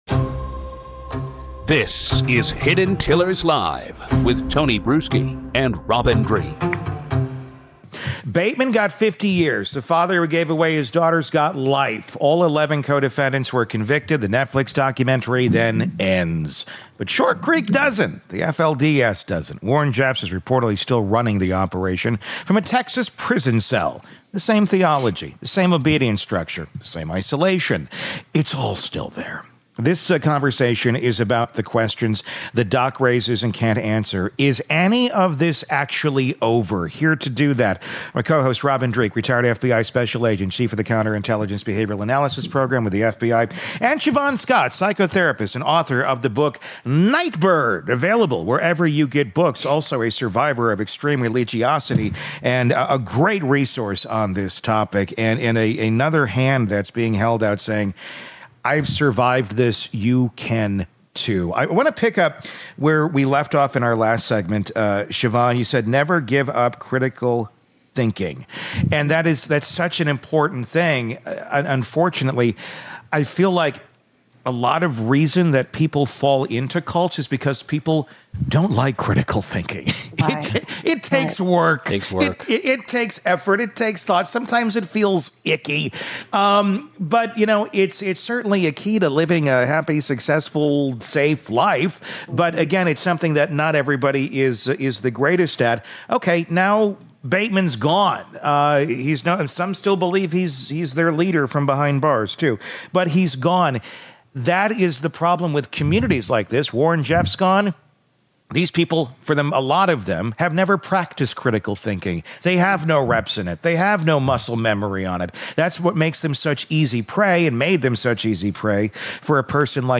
three-part panel